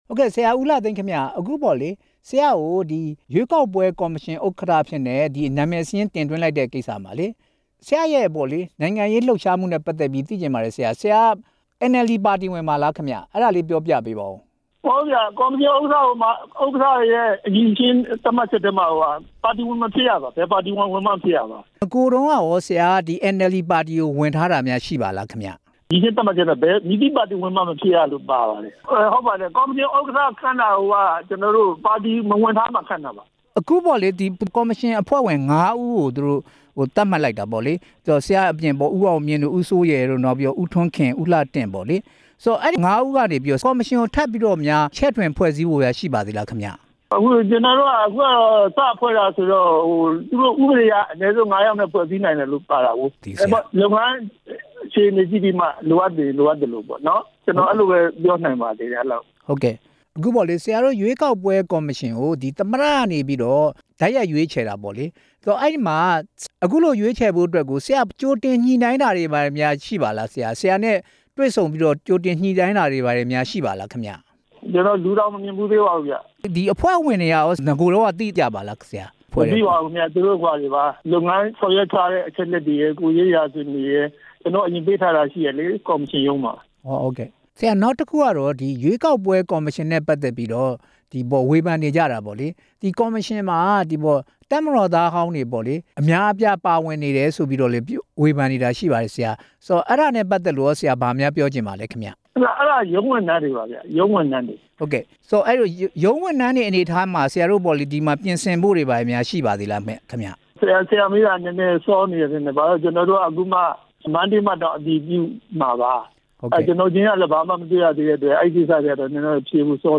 ရွေးကောက်ပွဲကော်မရှင်ဥက္ကဌသစ် အဆိုပြုခံရသူ ဦးလှသိန်းနဲ့ မေးမြန်းချက်